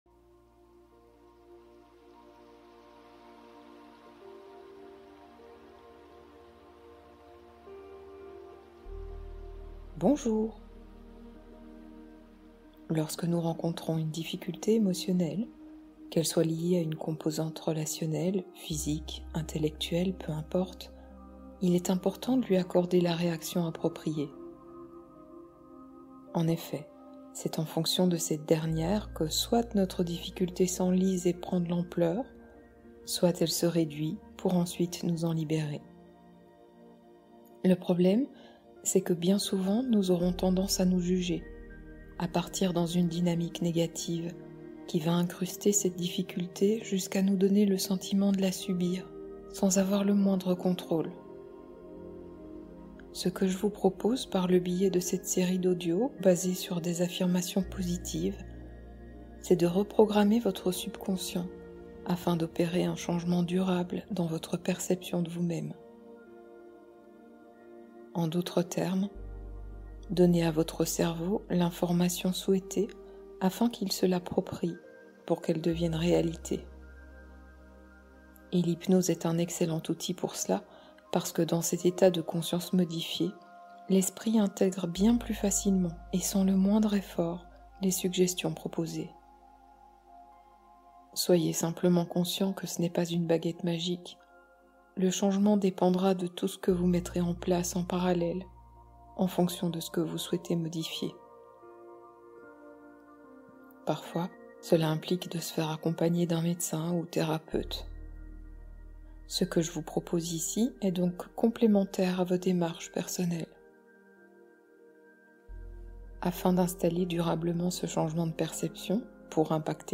Lâcher le contrôle : hypnose de libération